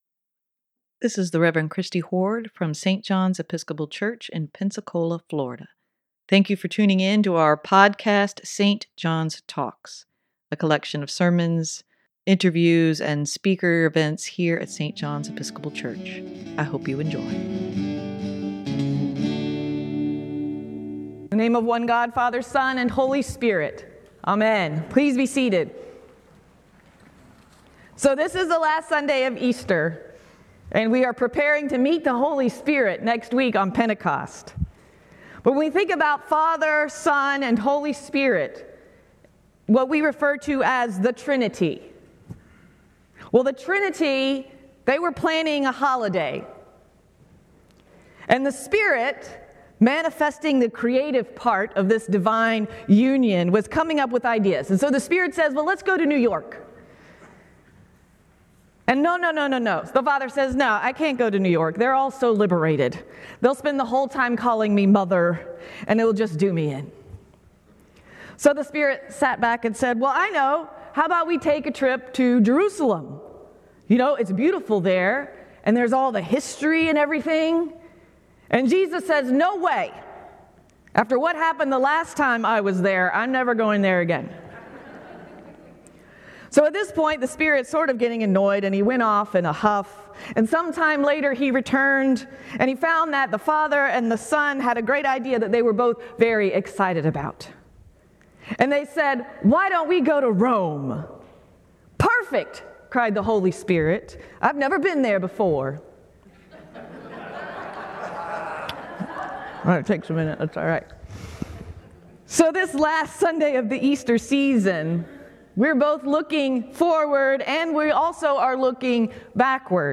sermon-5-21-23.mp3